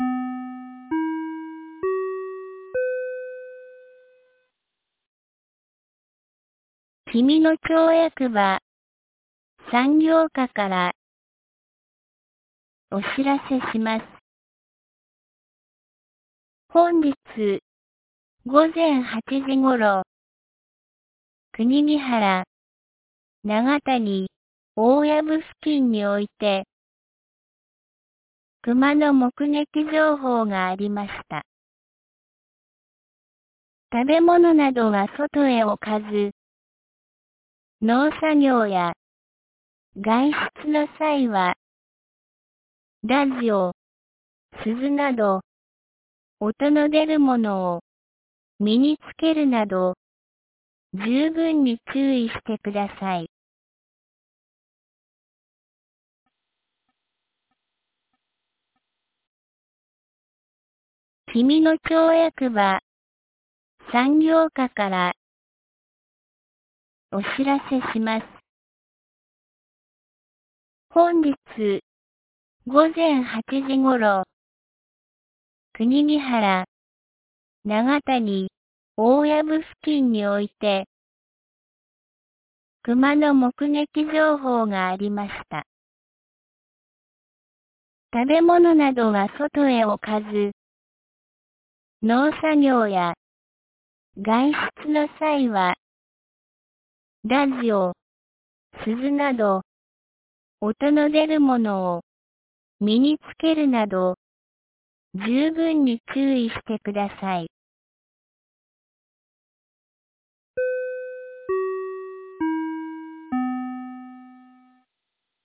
2021年05月12日 17時07分に、紀美野町より東野上地区、志賀野地区へ放送がありました。